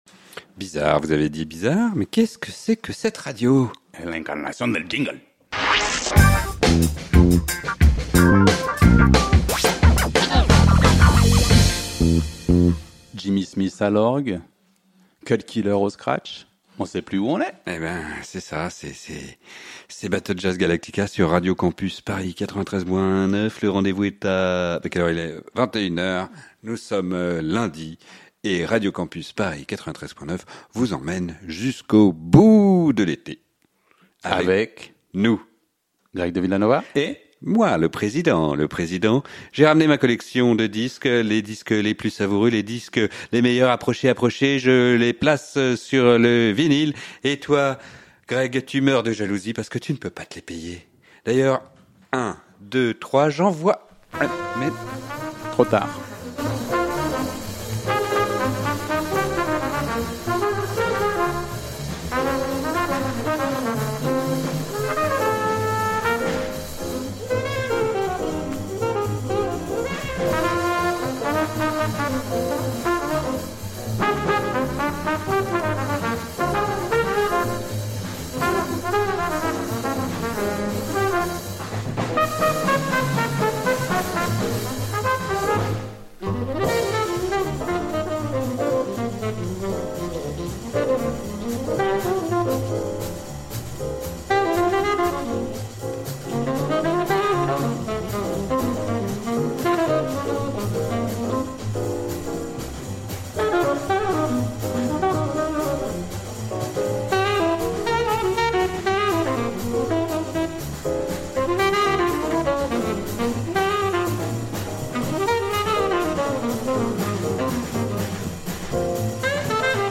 Ambiance moite, cuivres acides.
La compétition s’installe au creux des basses.
Classique & jazz